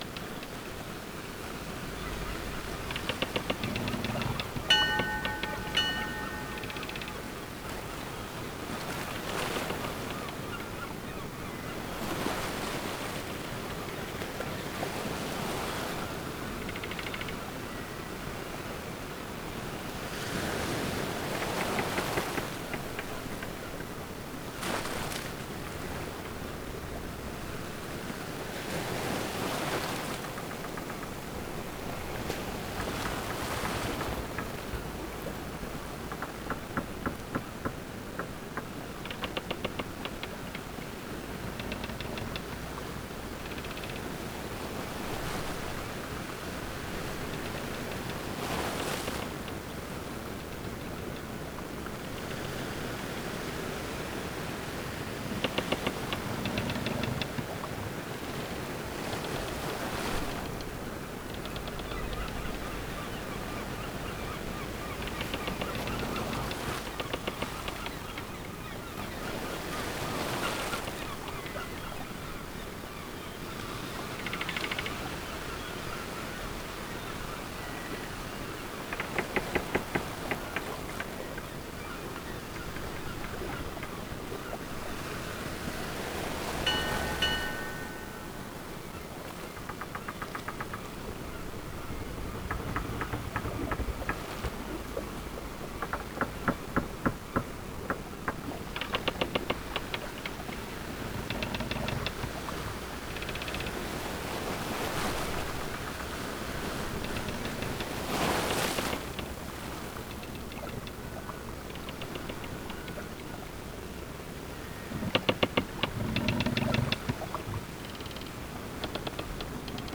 pirate_amb.wav